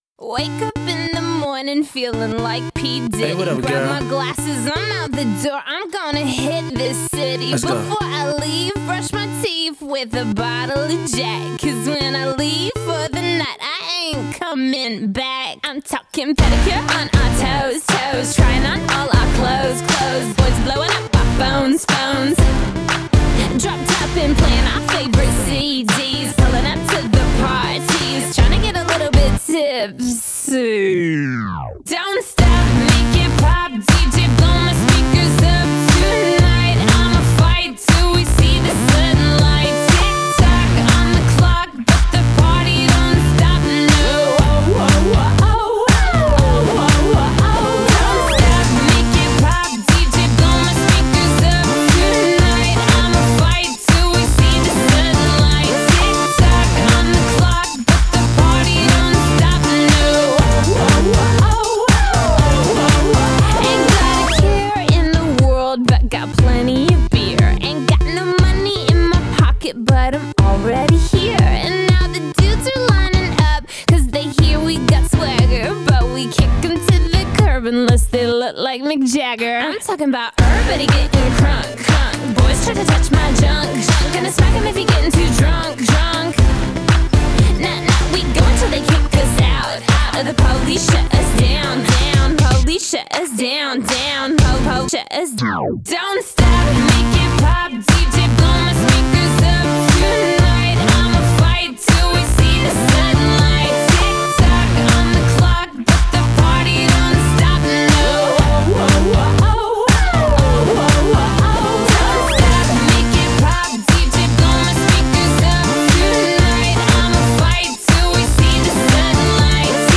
Pop 2000er